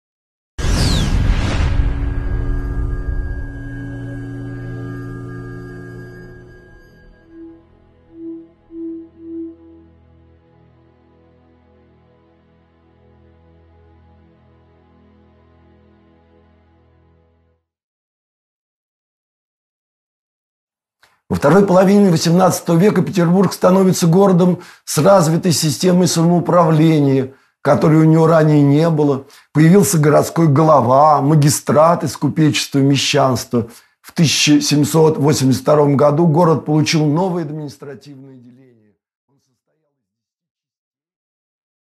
Аудиокнига Санкт-Петербург времен Екатерины II. Эпизод 4 | Библиотека аудиокниг